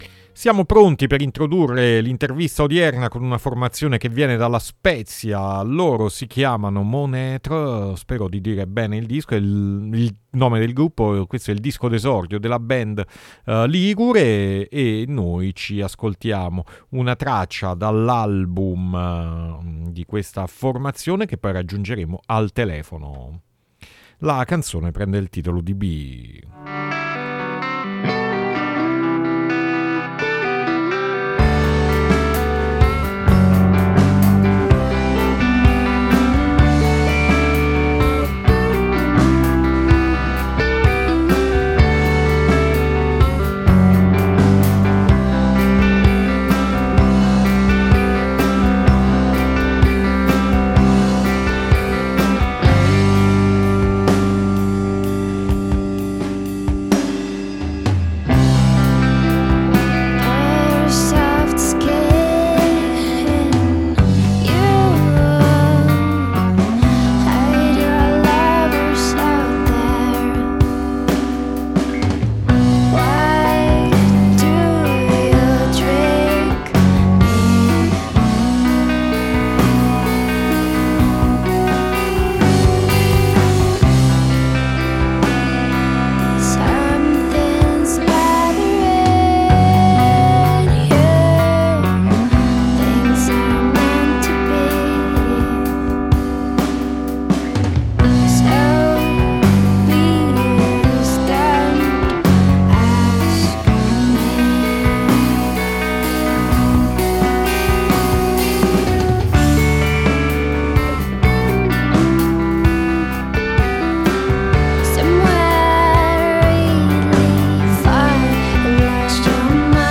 Intervista Monêtre 9-3-2020 | Radio Città Aperta